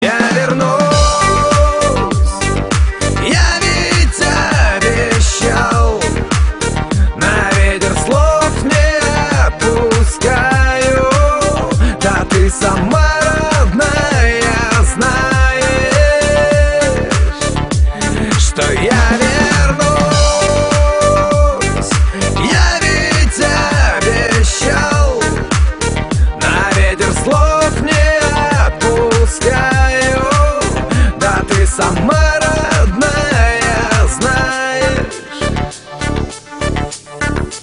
поп
русский шансон